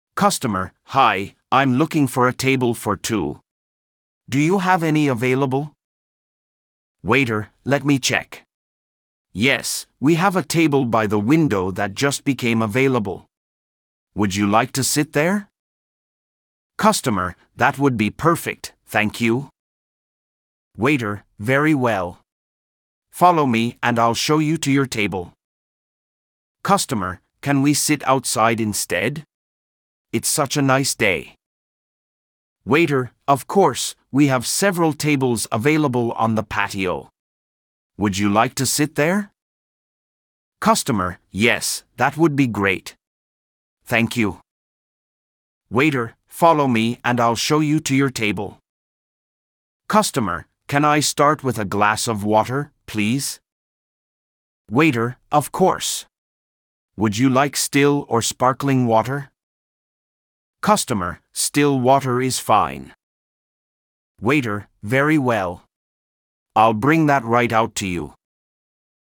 Diyalog 1: